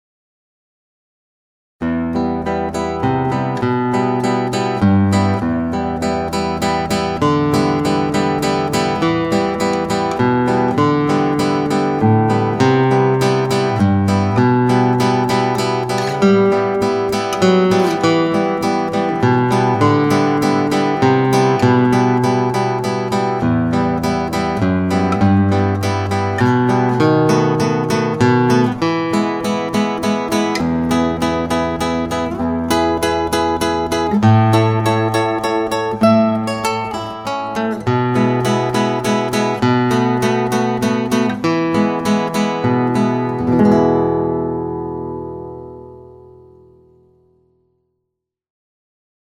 Gitarre, akustische Gitarre
Klassischer Stil